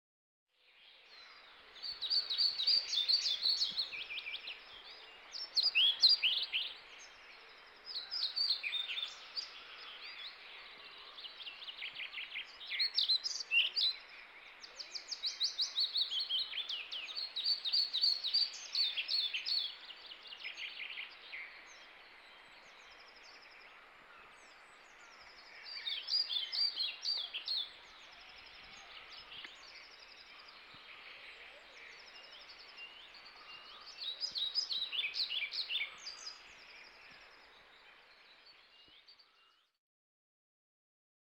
Muuttolintukalenteri: kirjosieppo tuo kevään pihalle